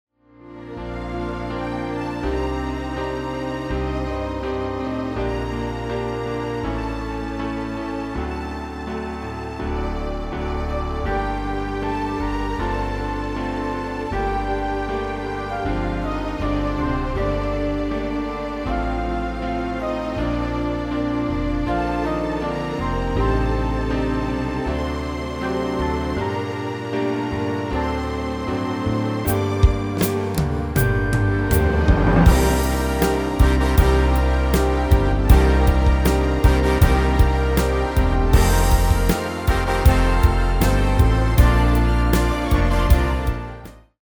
Demo/Koop midifile
Genre: Nederlandse artiesten pop / rock
Toonsoort: Gm
- Géén vocal harmony tracks
Demo = Demo midifile
Demo's zijn eigen opnames van onze digitale arrangementen.